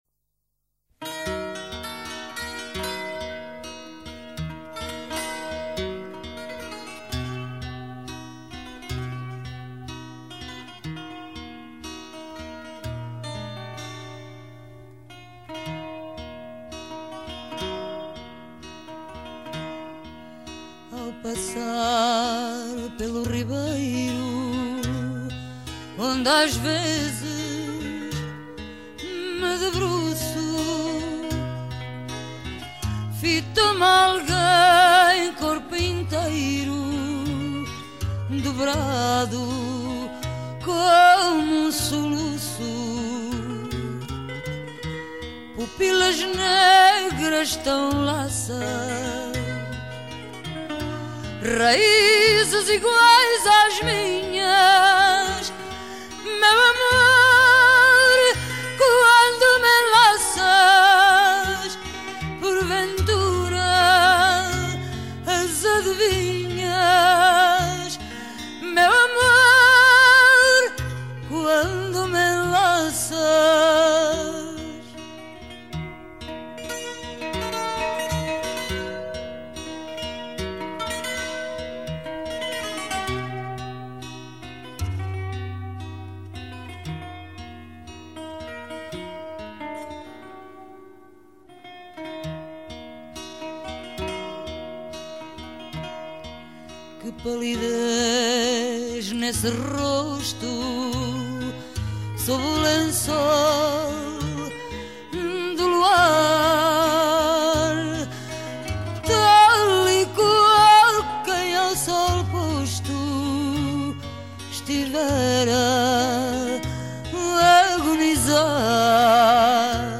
Un fado pour aujourd’hui, 6 février 2014.
guitare portugaise
guitare classique. Enregistrement : Lisbonne, janvier 1969.